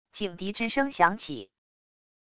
警笛之声响起wav音频